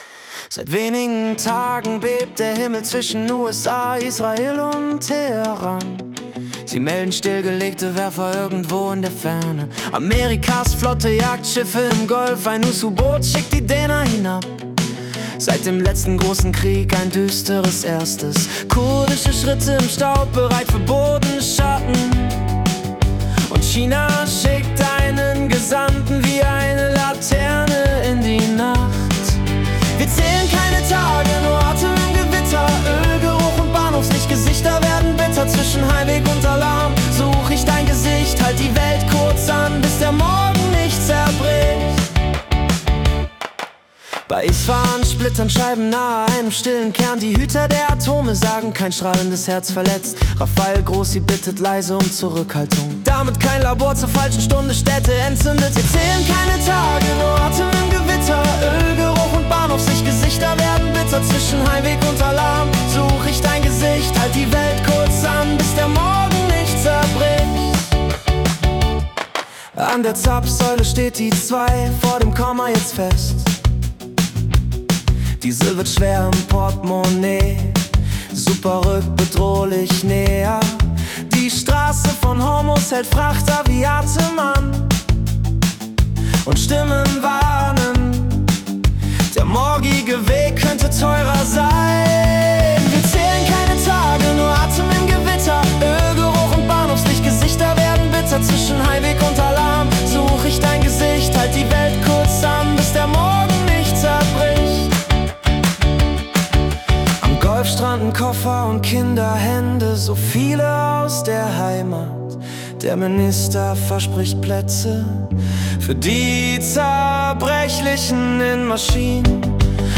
März 2026 als Singer-Songwriter-Song interpretiert.